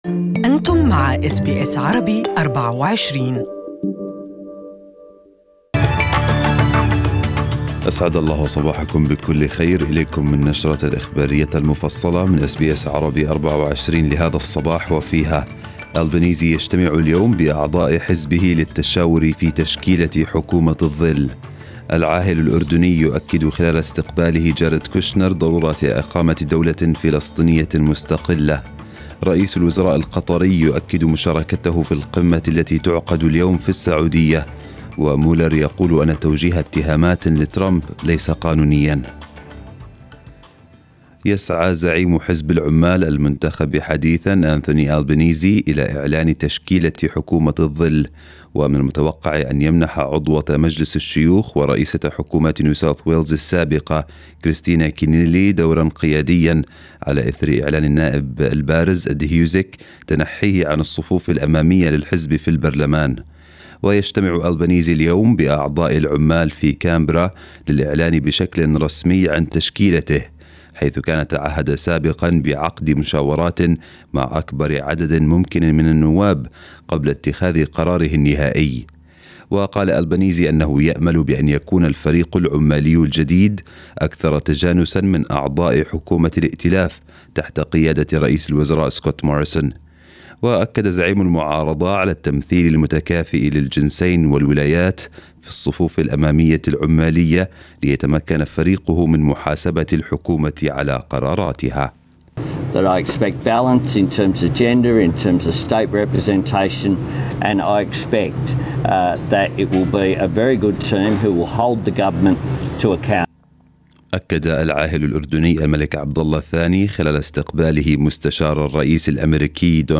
Morning news bulletin in Arabic 30/5/2019